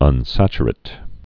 (ŭn-săchə-rĭt)